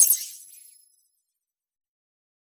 Deep HiTech UI Sound 1.wav